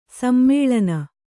♪ sammēḷana